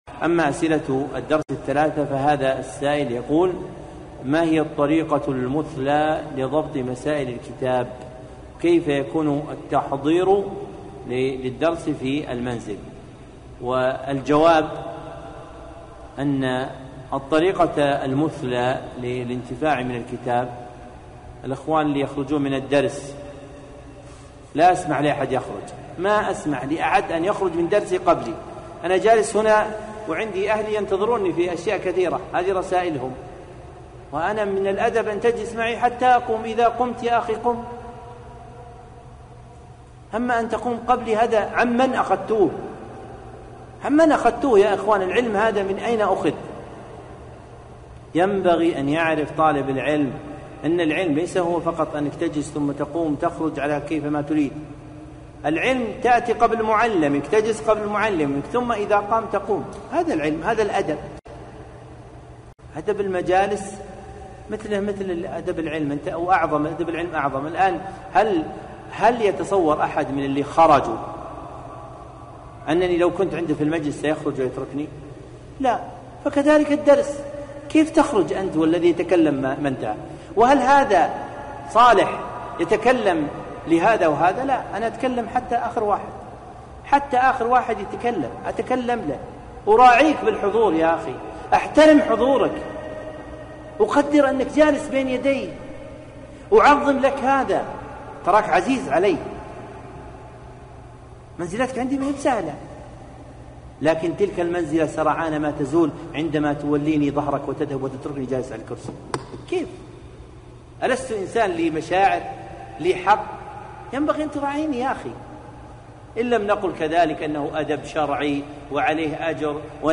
موعظة بليغة